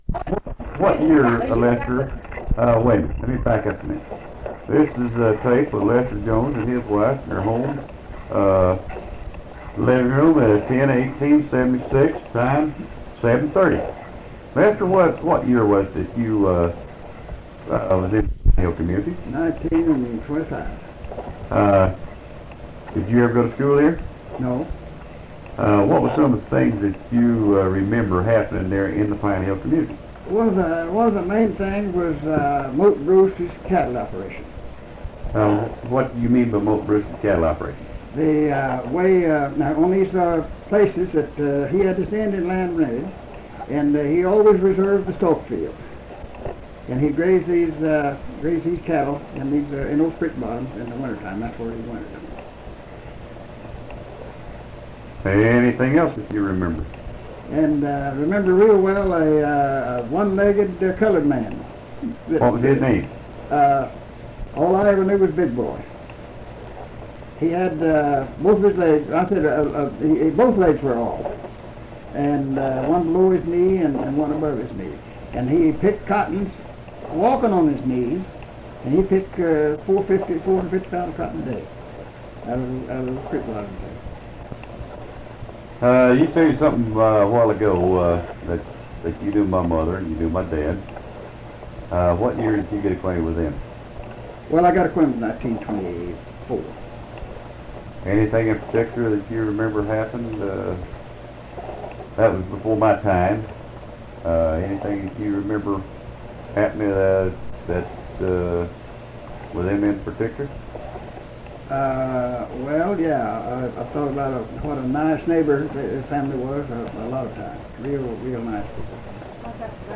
Oral History Archive | Pinehill Community